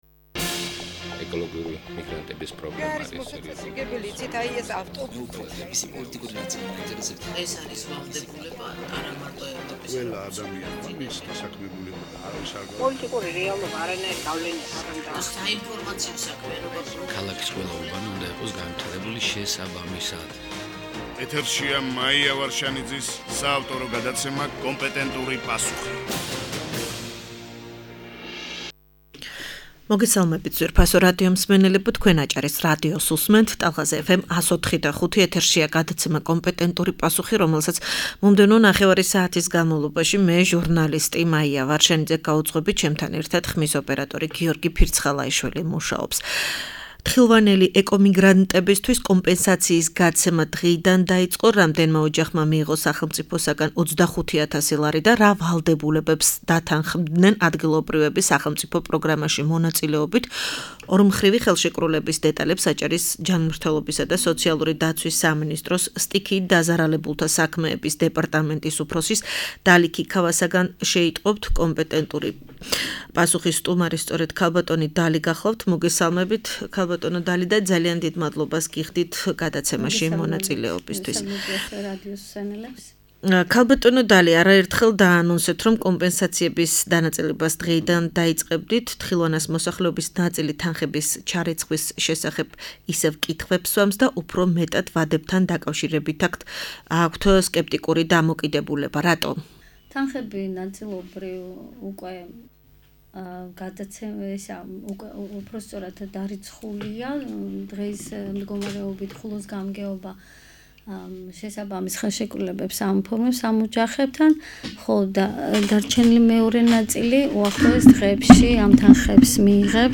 პირდაპირ ეთერში ხულოს მუნიციპალიტეტის გამგებელი ბესიკ ბაუჩაძე ჩაერთვება.